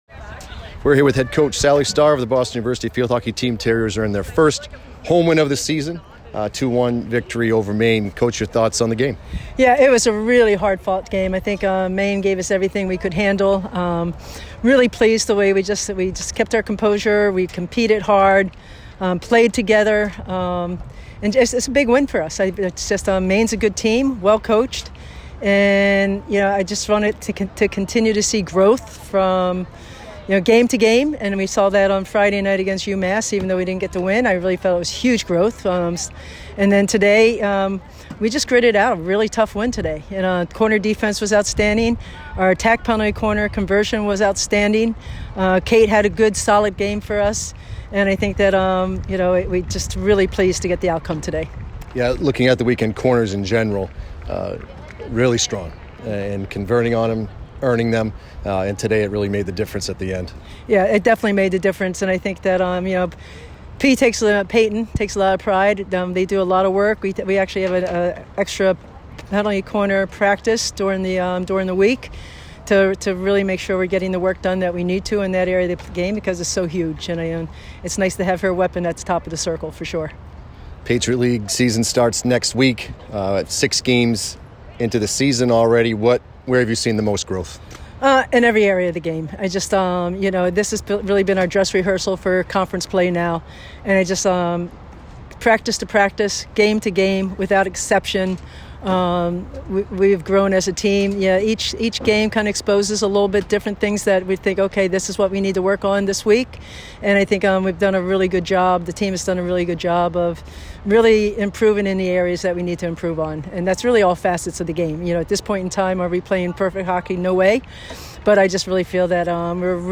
Field Hockey / Maine Postgame Interview